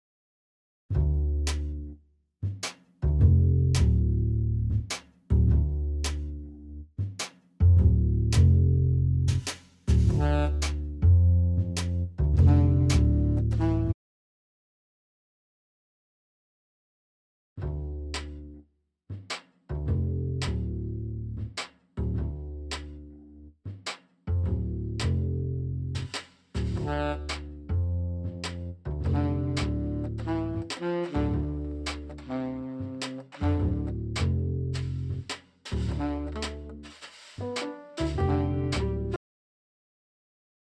sound distortion.mp3